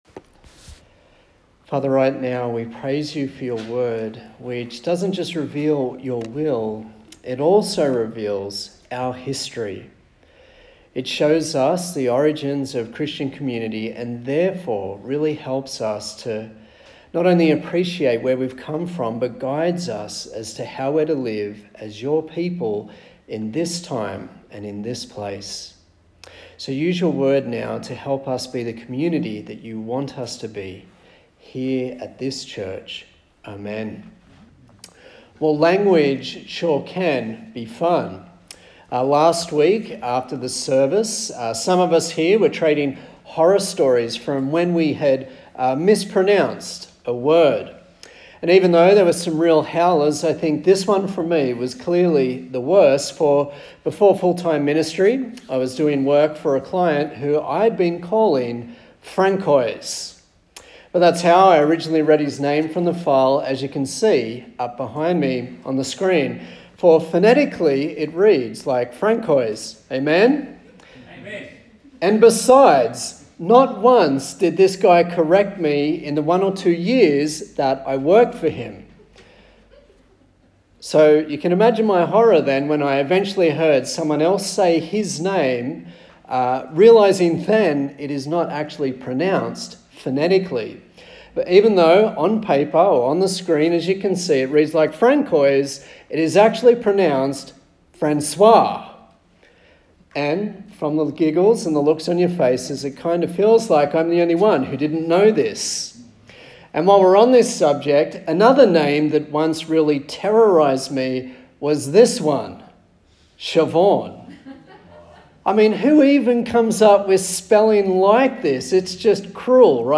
Acts Passage: Acts 11:19-30 Service Type: Sunday Morning